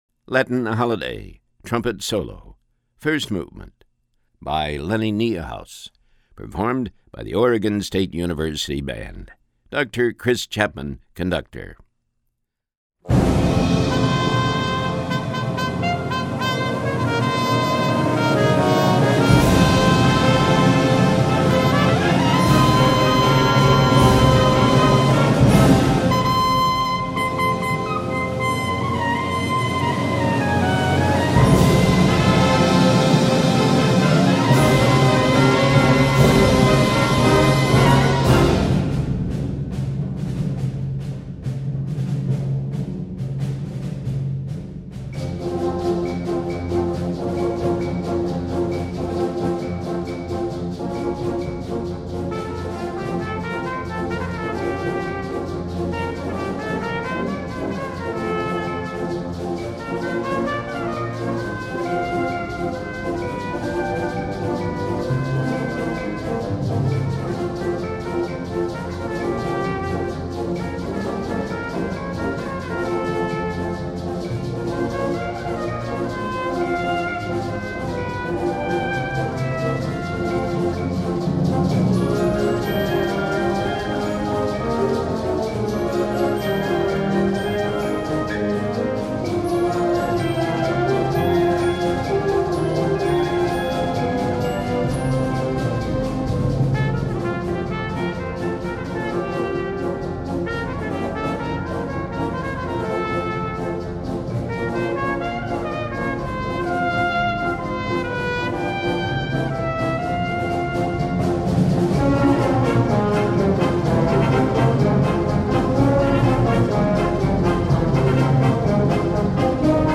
Voicing: Trumpet w/ Band